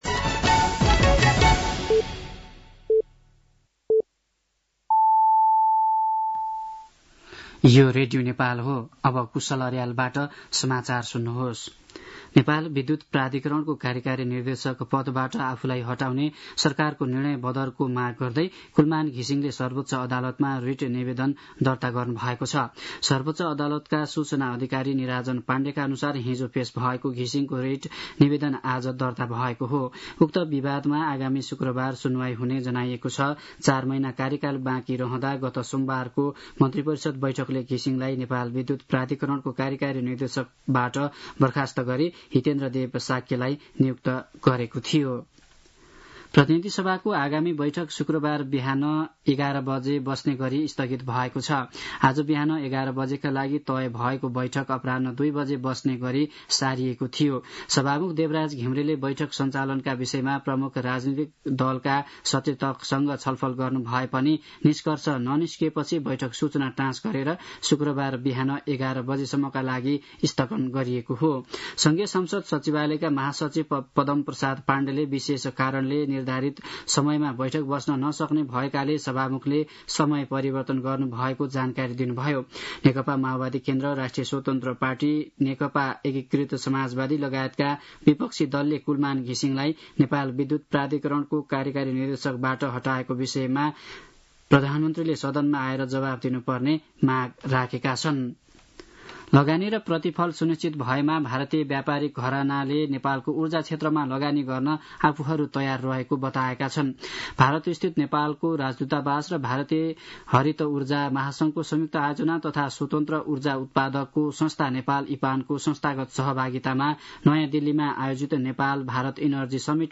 साँझ ५ बजेको नेपाली समाचार : १४ चैत , २०८१
5-pm-nepali-news-12-14.mp3